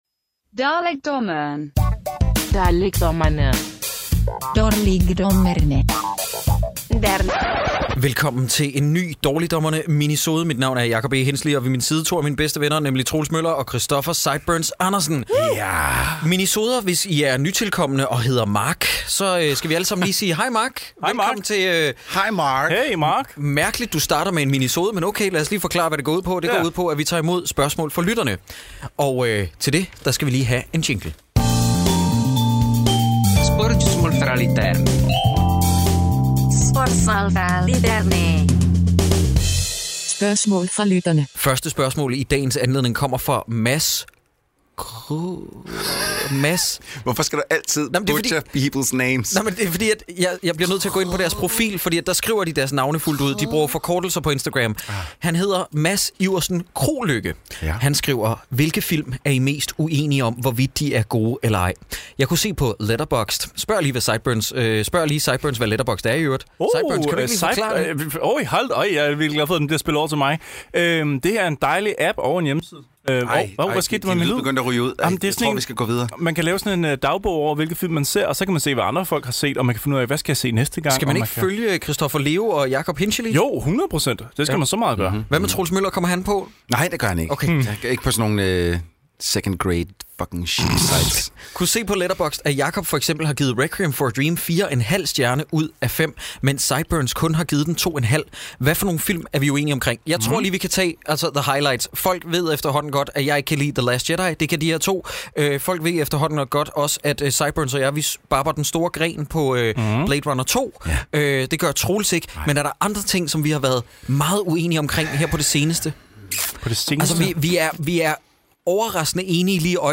Der er derfor 100p kaos i vente, og faktisk nogle rigtige gode spørgsmål fra jer.